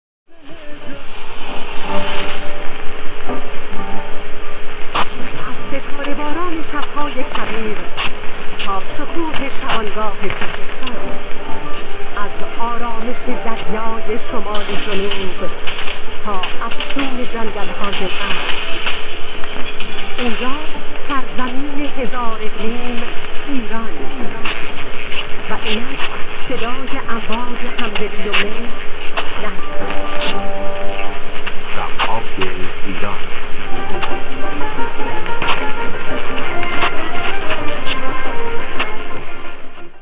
Loggings from Quoddy House [QH] near Lubec, ME